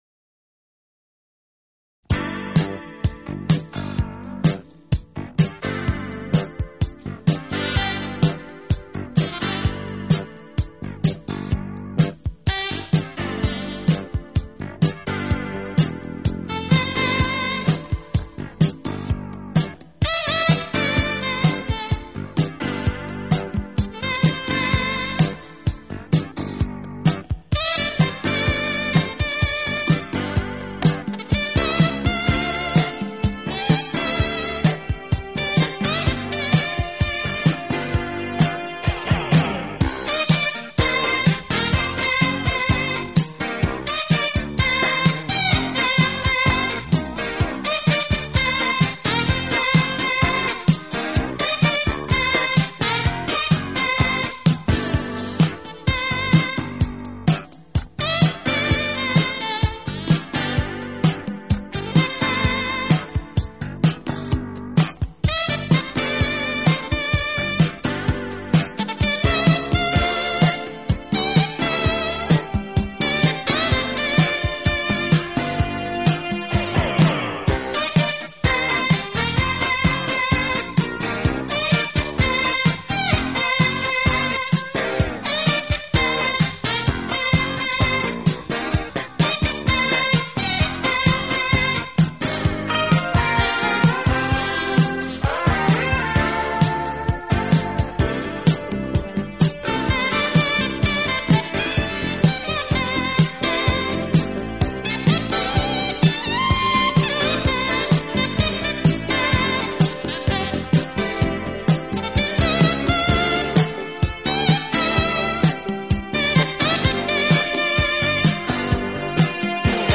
【爵士萨克斯】